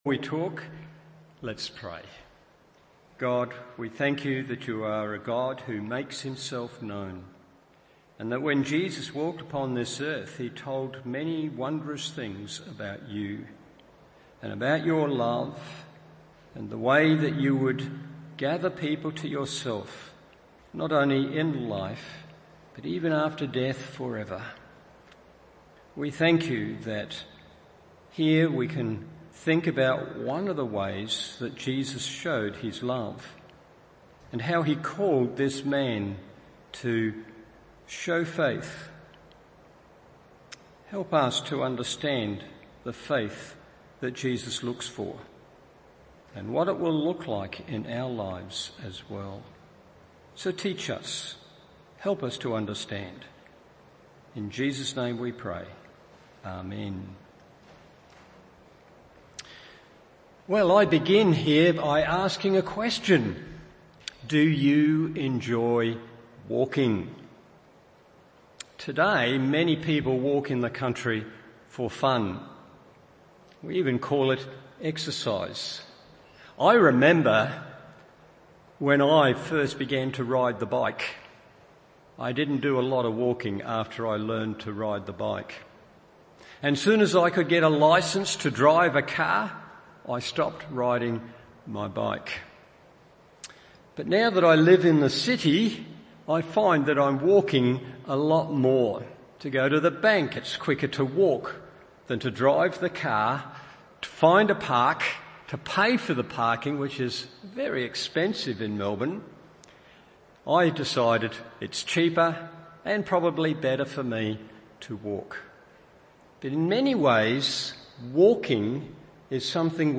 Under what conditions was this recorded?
Evening Service Meeting Jesus